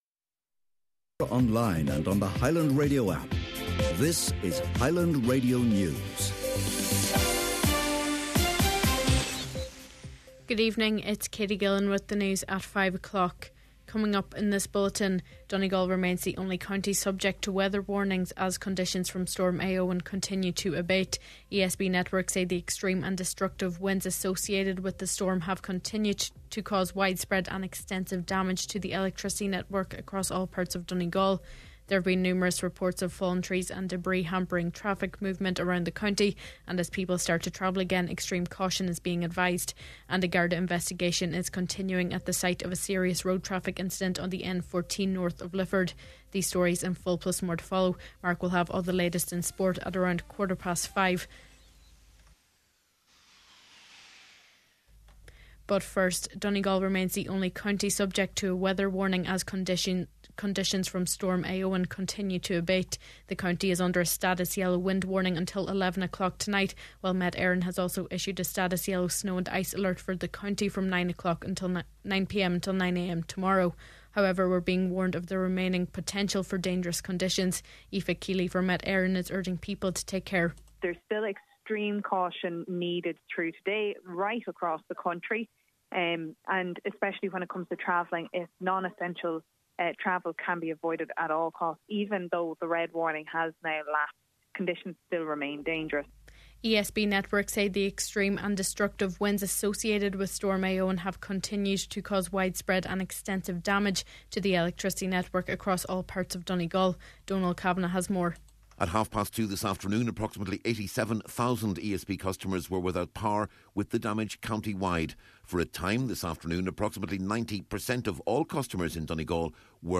Main Evening News, Sport and Obituaries – Friday January 25th